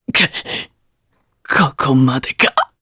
Roi-Vox_Dead_jp.wav